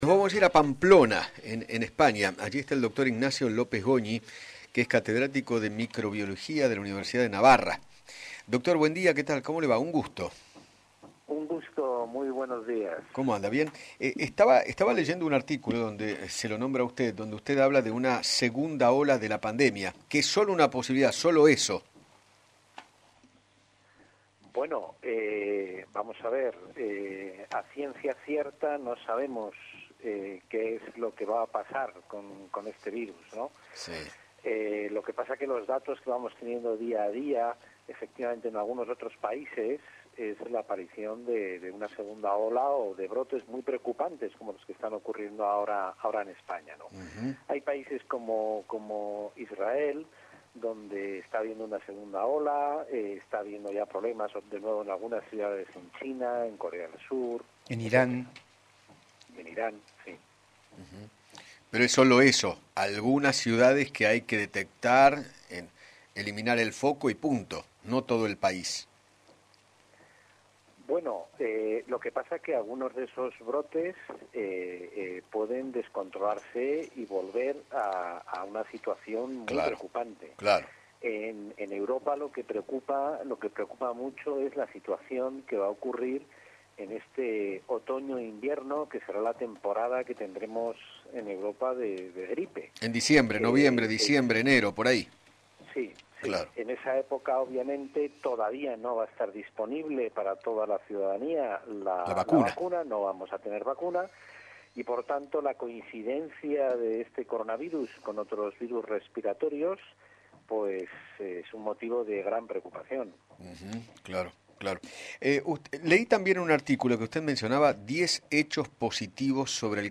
dialogó con Eduardo Feinmann sobre los rebrotes de Covid-19 en algunos países como España, Israel, Corea del Sur e Irán, y se refirió a la posibilidad de una segunda ola de la pandemia.